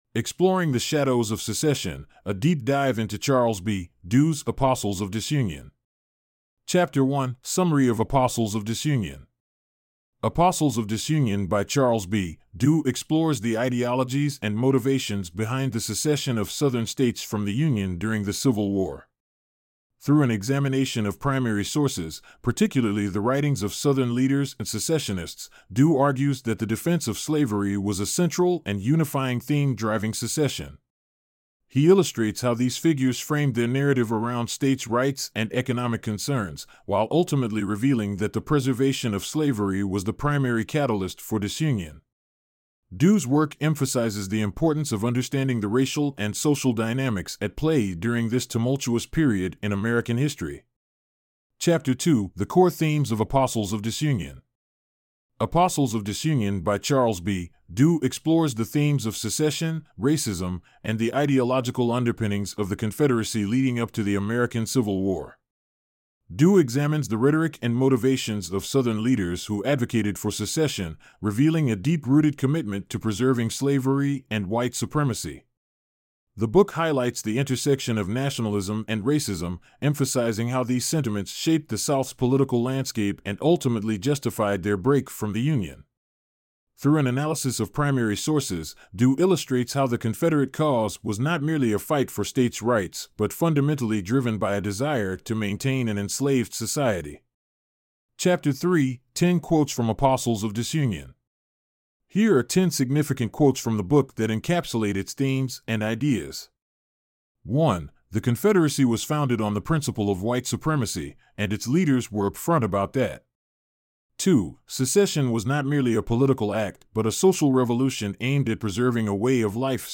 Reading Recap: Book Summaries